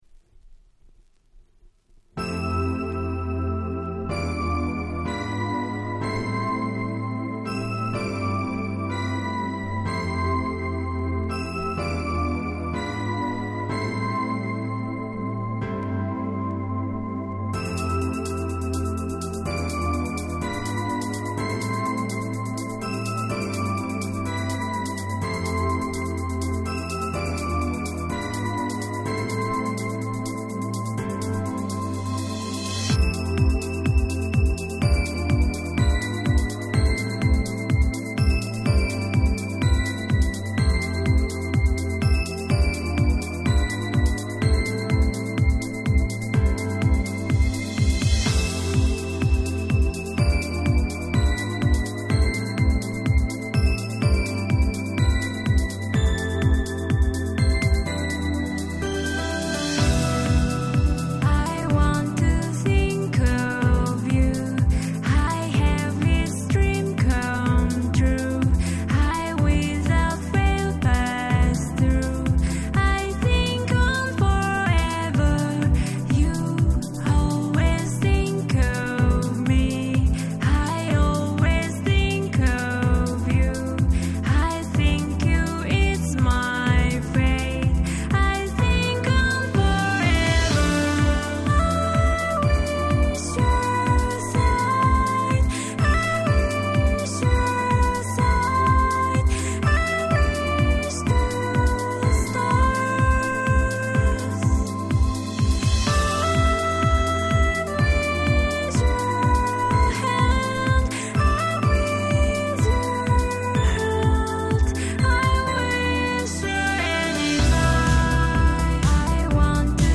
所謂「乙女系House」の最高峰。